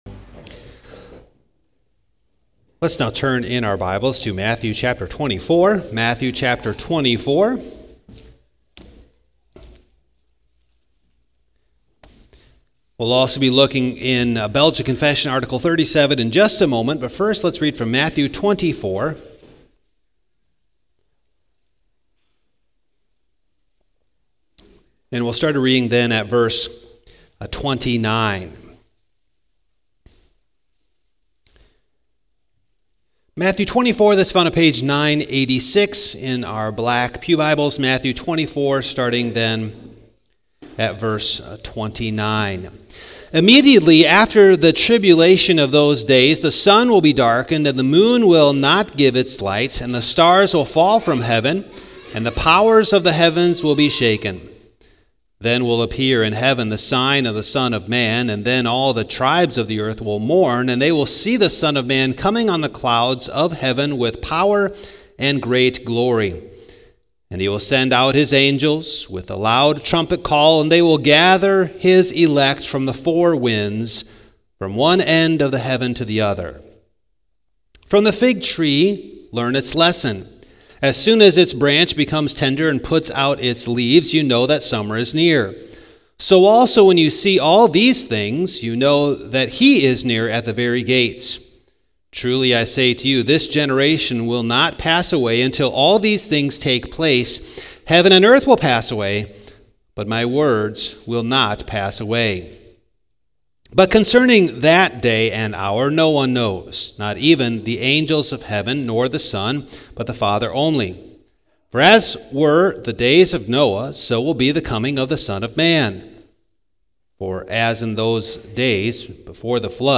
Passage: Matthew 24 :29-44 Service Type: Morning